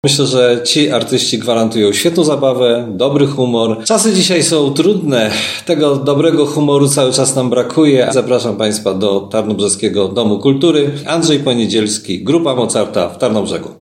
Wydarzenie odbywa się pod patronatem Prezydenta Miasta Tarnobrzega. mówi prezydent Tarnobrzega Dariusz Bożek.